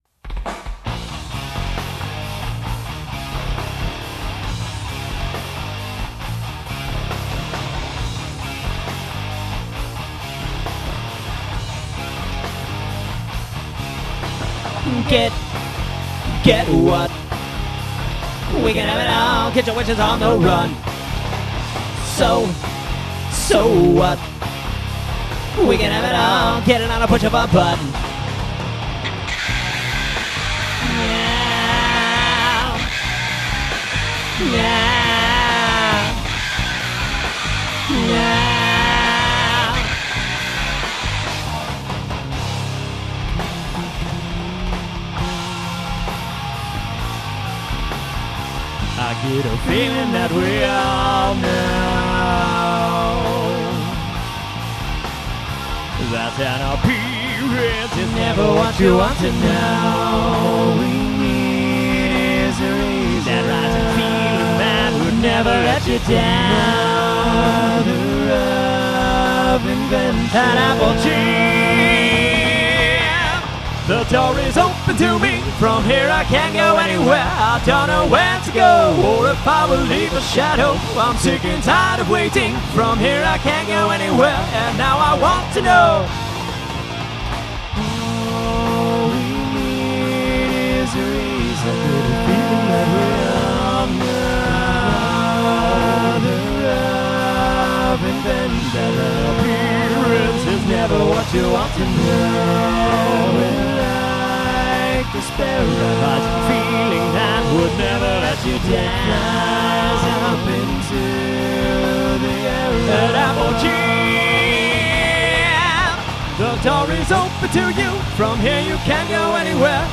Unfortunately we had to give our pro studio mic back to it's owner.